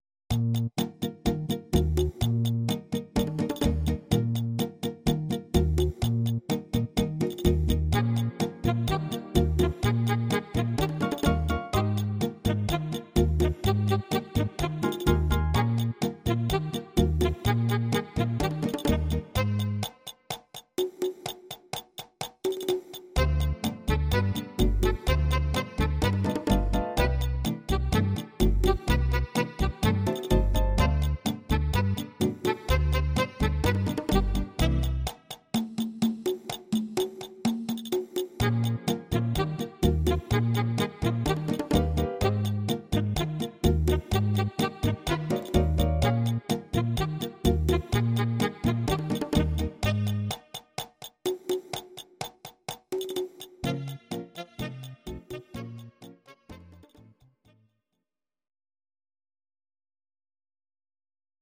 Please note: no vocals and no karaoke included.
Your-Mix: Jazz/Big Band (731)